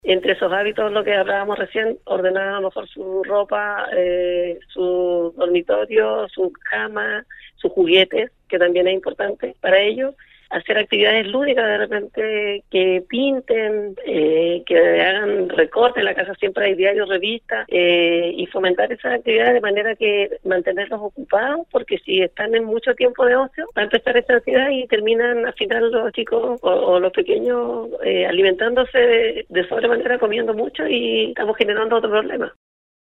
sostuvo un contacto telefónico con Nostálgica donde se refirió a la complejidad de tener a los hijos 100% de su tiempo en casa